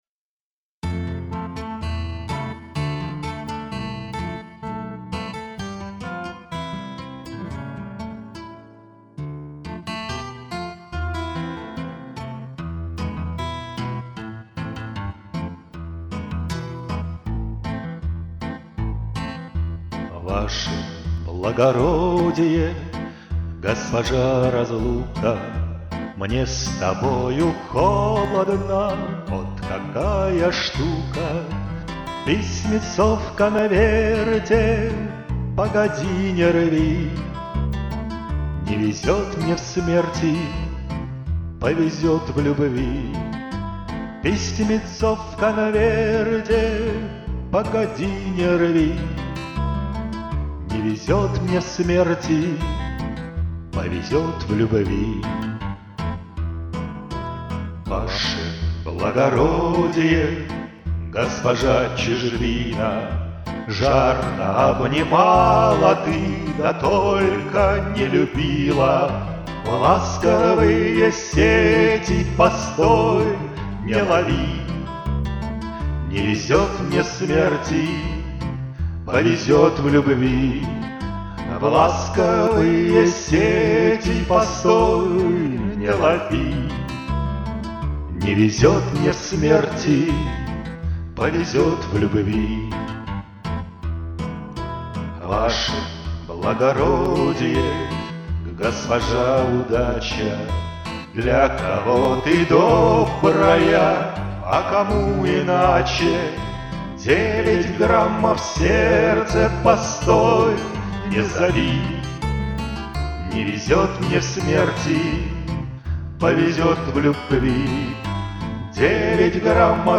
Я просто прописал несколько голосов.